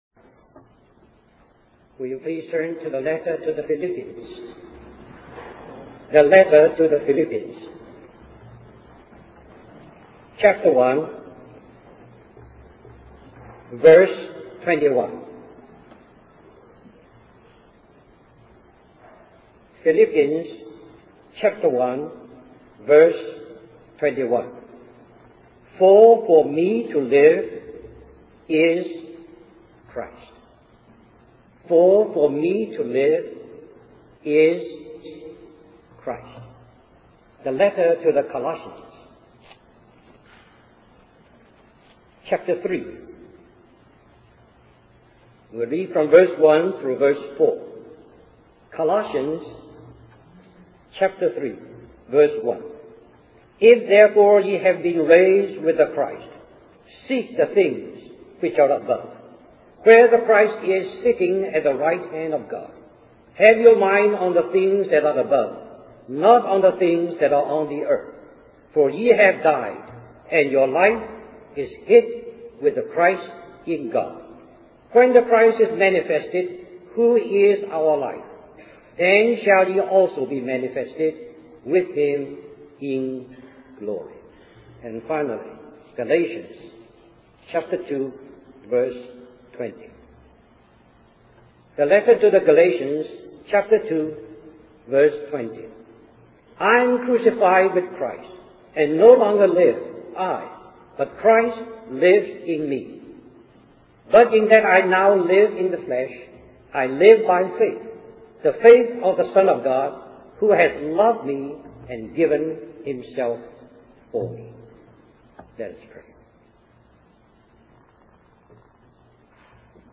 A collection of Christ focused messages published by the Christian Testimony Ministry in Richmond, VA.
1996 Christian Family Conference Stream or download mp3 Summary This message is also printed in booklet form under the title